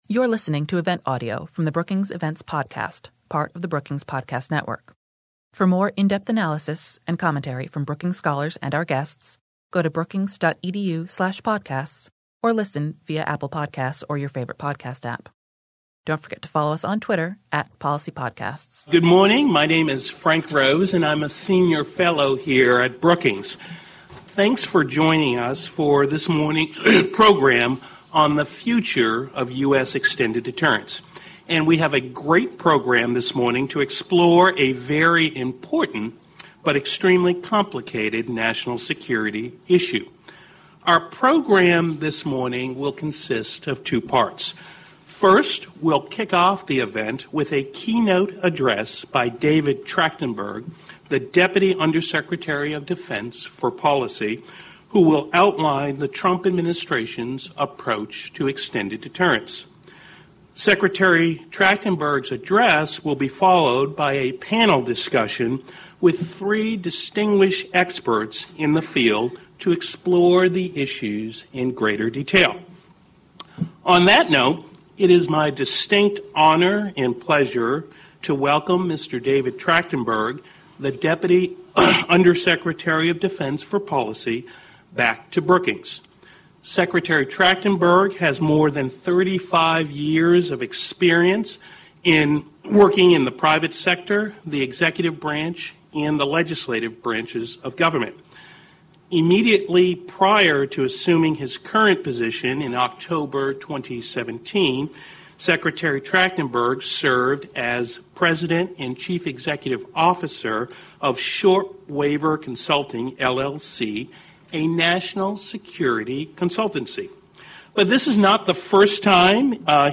On April 24, the Foreign Policy program at Brookings hosted an event featuring a keynote address by Deputy Undersecretary of Defense David Trachtenberg, followed by a panel discussion involving experts and former government officials, to explore the issue in greater detail.
Panel Discussion